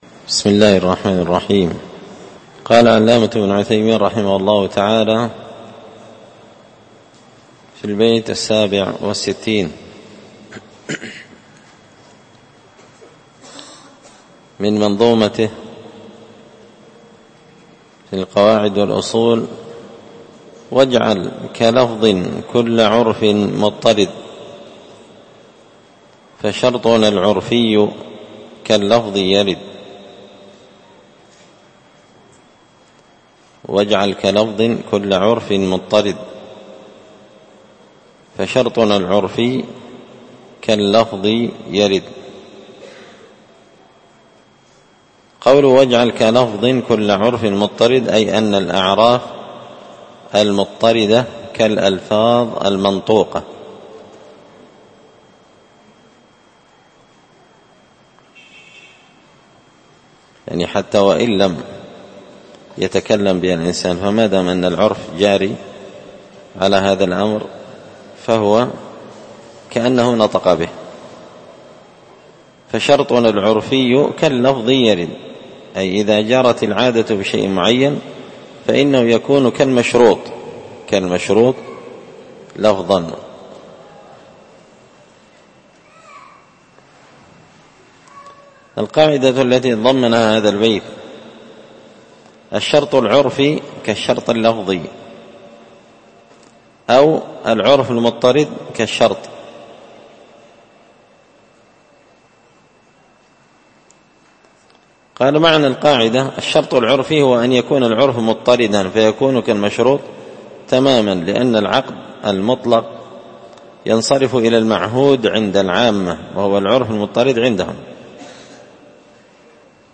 الدرس 37
مسجد الفرقان